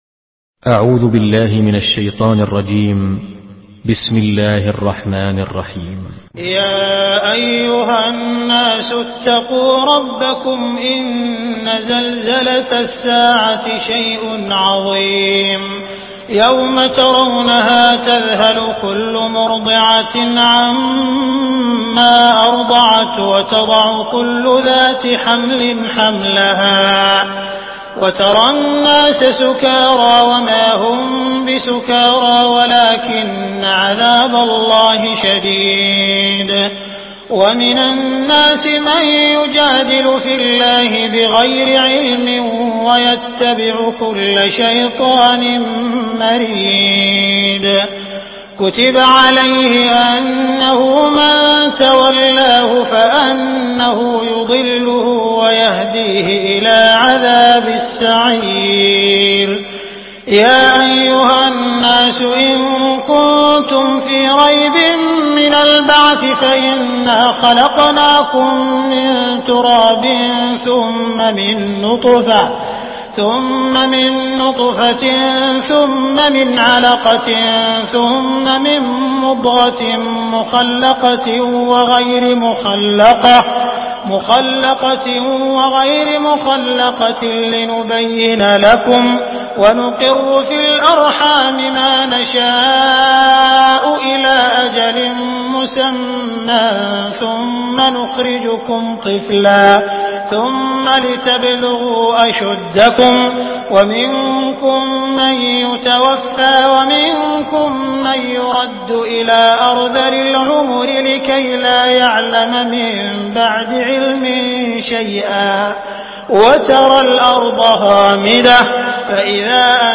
Surah Al-Haj MP3 in the Voice of Abdul Rahman Al Sudais in Hafs Narration
Murattal